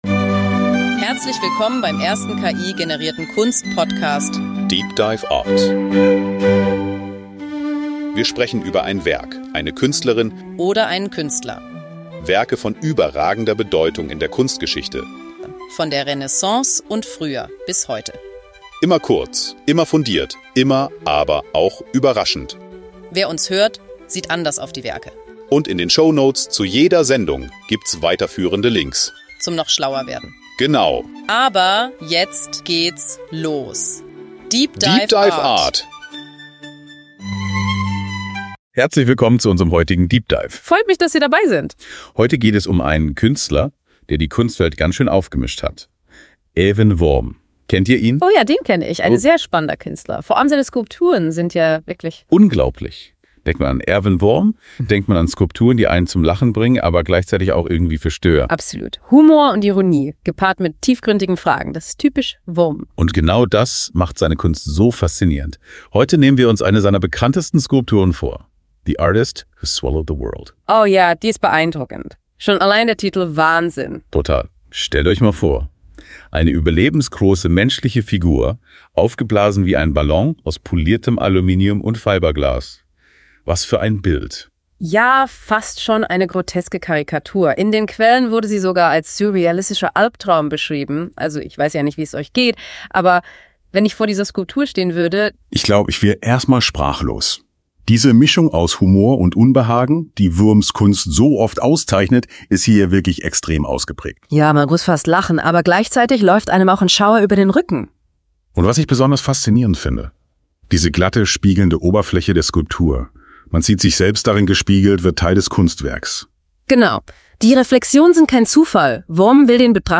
der erste voll-ki-generierte Kunst-Podcast.